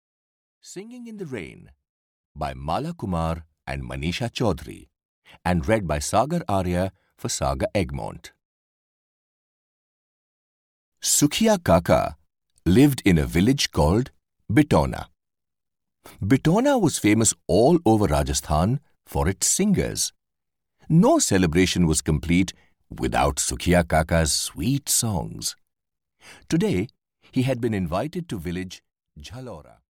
Singing in the Rain (EN) audiokniha
Ukázka z knihy